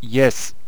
archer_select2.wav